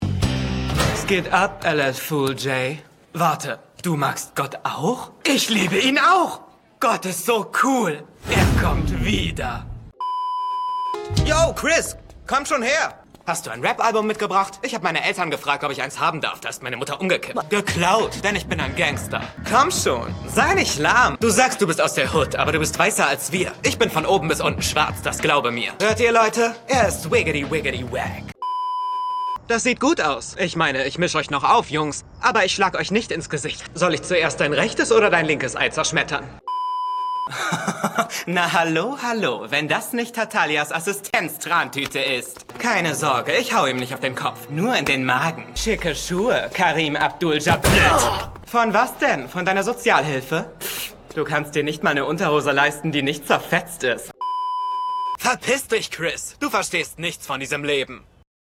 hell, fein, zart
Jung (18-30)
Commercial (Werbung)